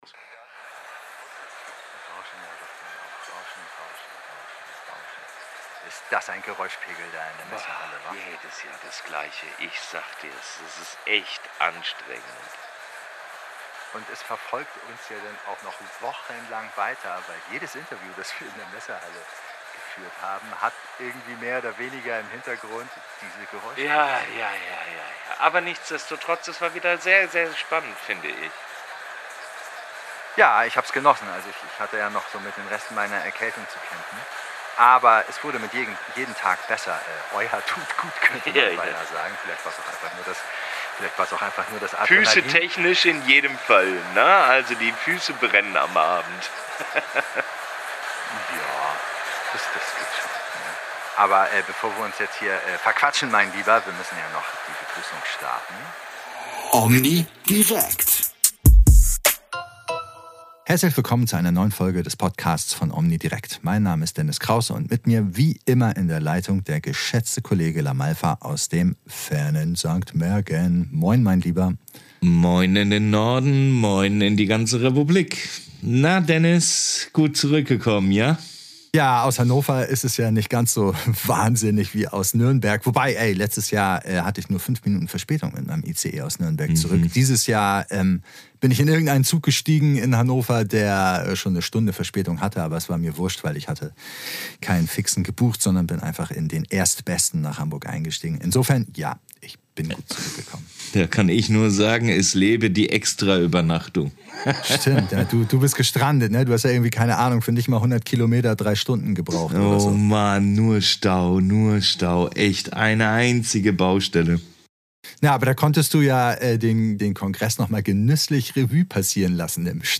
Ein Beispiel für eine herausfordernde akustische Situation unserer Redaktion gefällig?